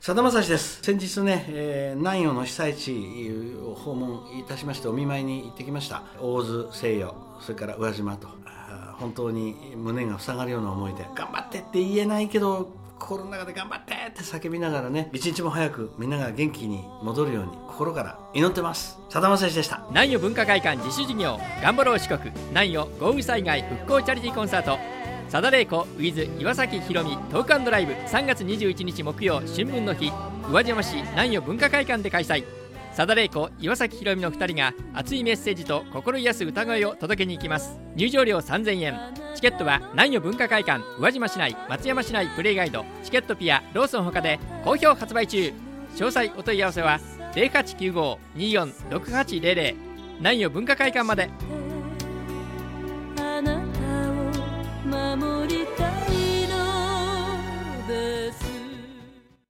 「さだまさし」さん応援コメント